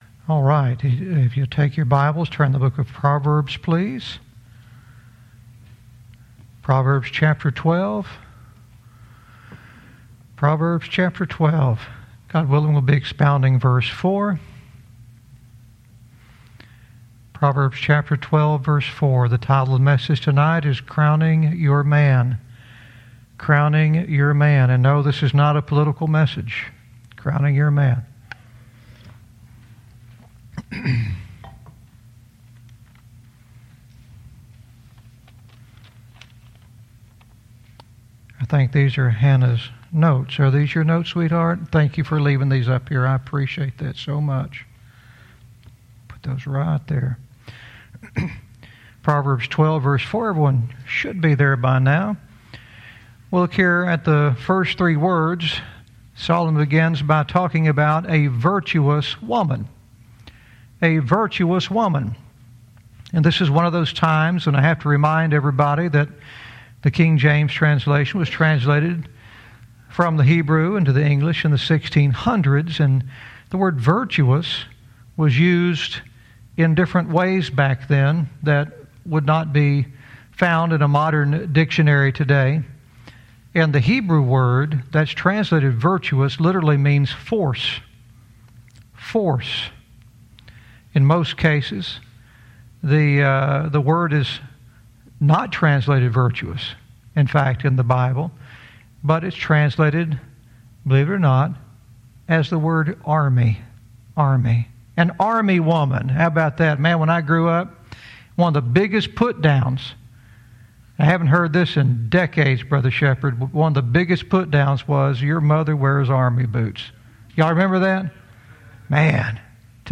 Verse by verse teaching - Proverbs 12:4 "Crowning Your Man"